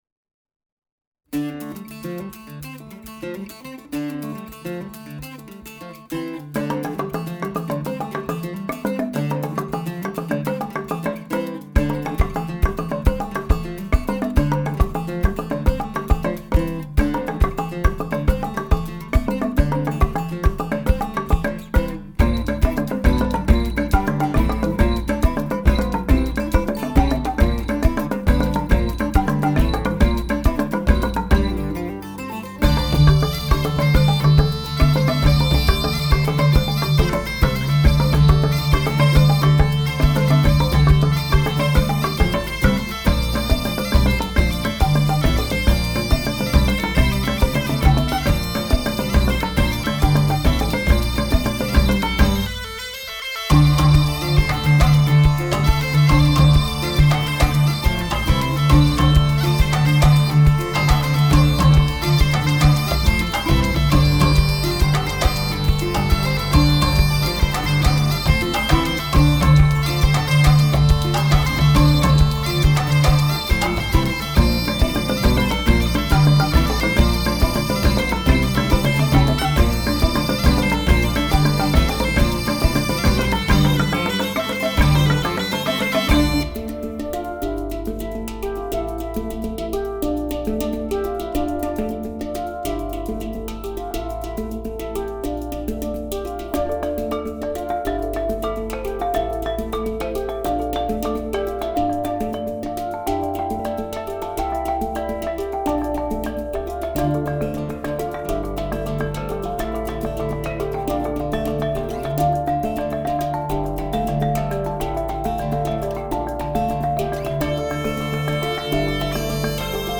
LA TXALAPARTA COMO PUENTE ENTRE RAÍZ Y HORIZONTES